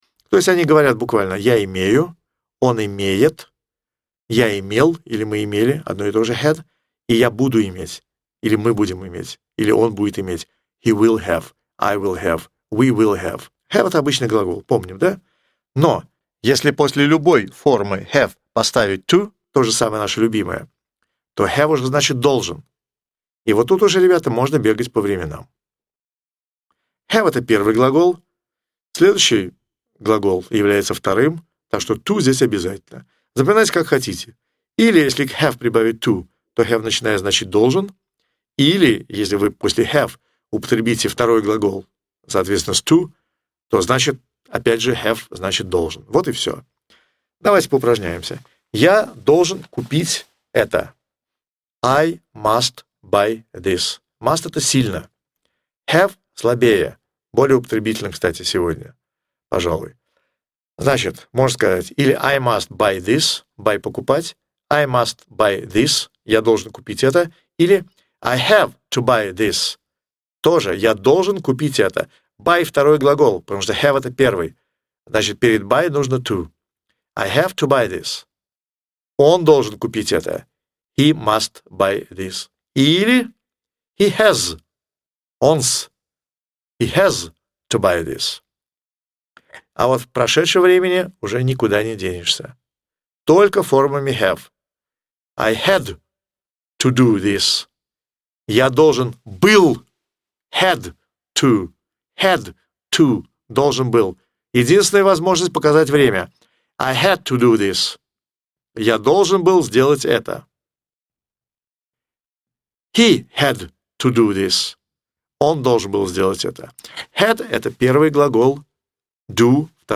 Аудиокурс английского языка